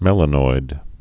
(mĕlə-noid)